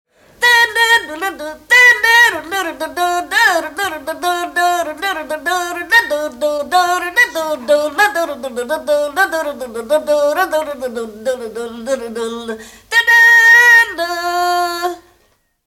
Műfaj Hangszeres
Részl.műfaj Hangszerutánzás
Helység Szany
Gyűjtő(k) Sárosi Bálint